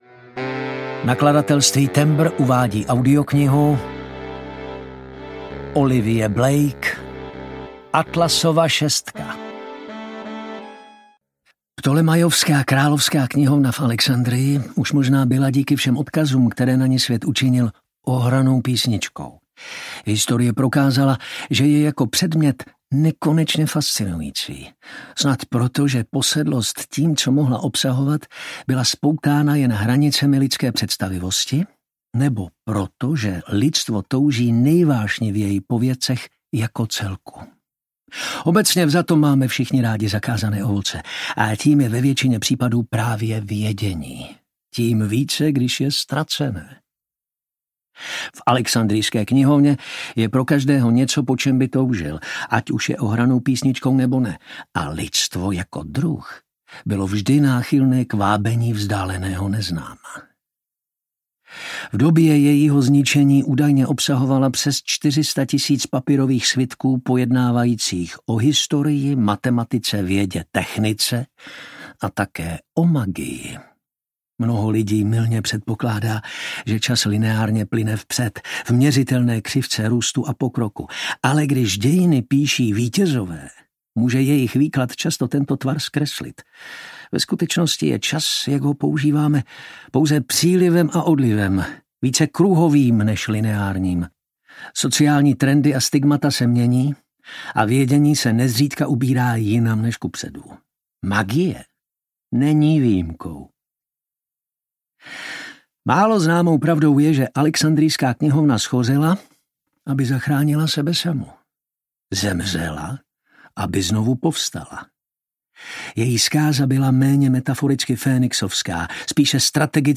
Atlasova šestka audiokniha
Ukázka z knihy
atlasova-sestka-audiokniha